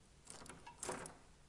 Lycée Queneau " Queneau lock
描述：一个cléd'uneporte
Tag: 钥匙 关闭